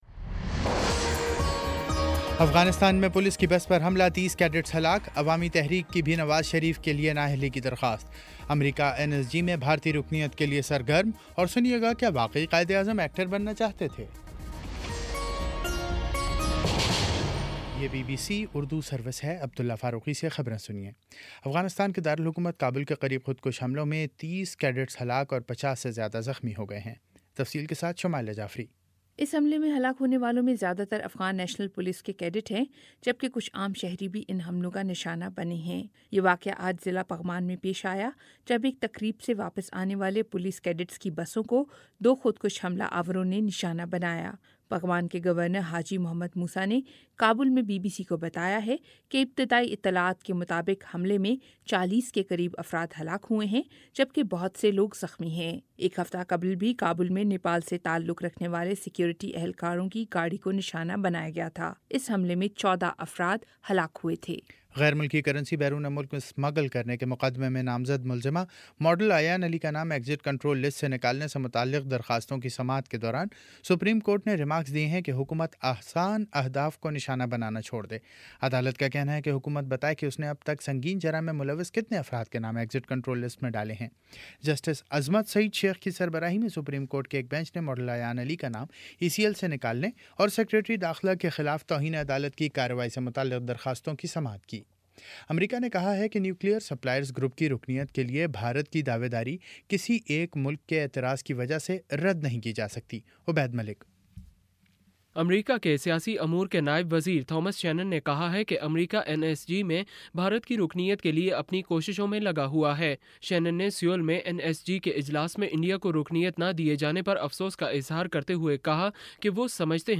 جولائی 20 : شام سات بجے کا نیوز بُلیٹن